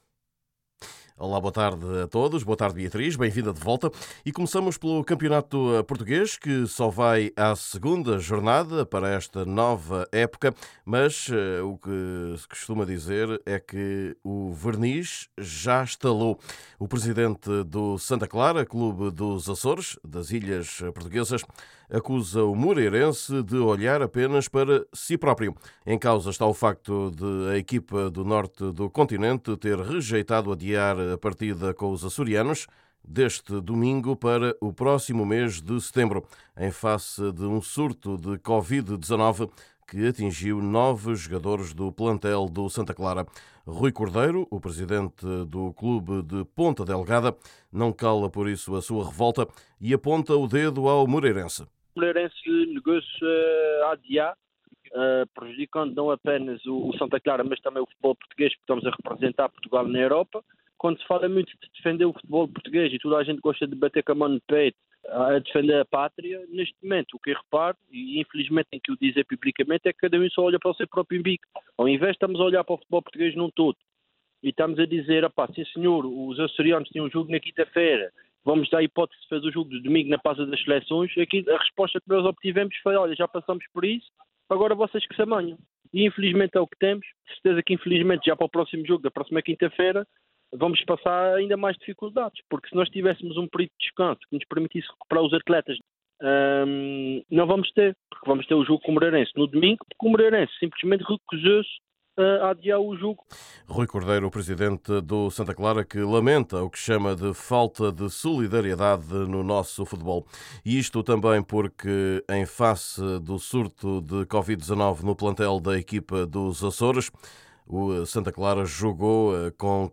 Jorge Jesus, o treinador das águias fala sobre o regresso dos adeptos ao estádio, ainda em plena pandemia.